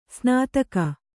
♪ snātaka